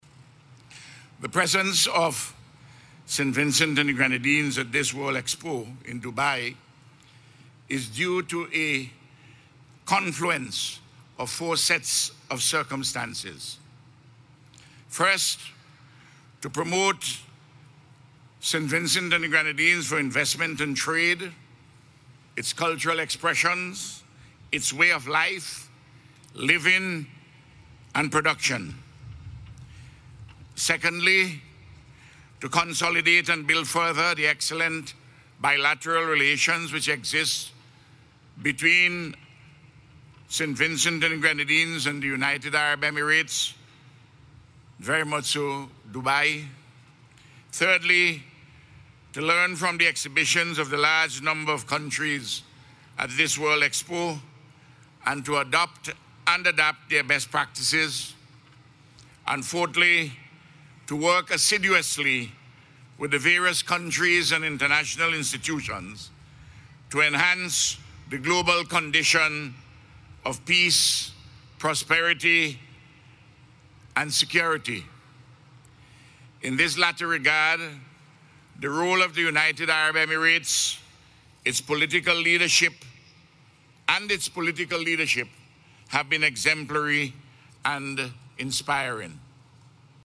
The Prime Minister was speaking at the National Day celebrations at the Dubai Expo this morning
RALPH-DUBAI-1.mp3